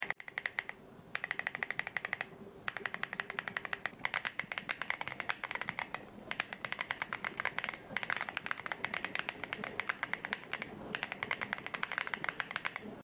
The sound profile largely depends on the switch type, here’s a comparison of typing sounds from different Akko switches, tap the product images below to hear how each switch sounds in action:
Akko Creamy Purple Pro Switch (Tactile)
How-Loud-Are-Mechanical-Keyboards-Akko-V3-Creamy-Purple-Pro-Switch-MonsGeek.mp3